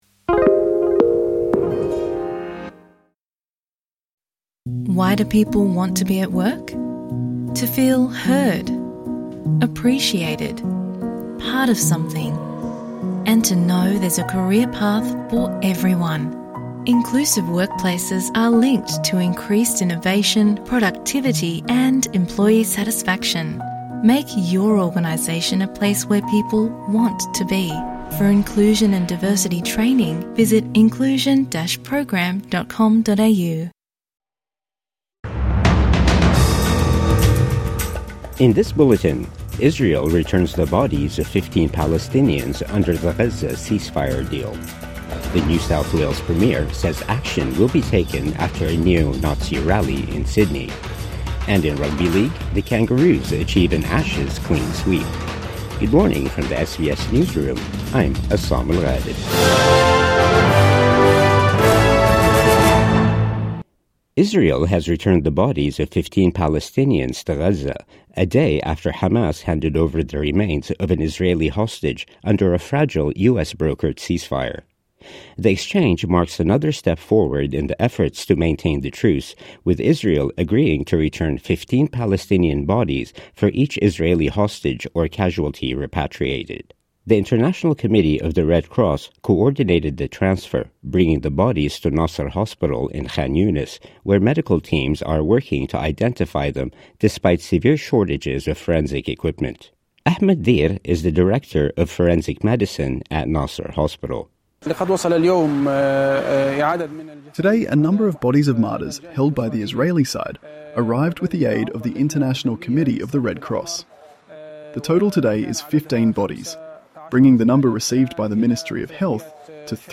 Morning News Bulletin 9 November 2025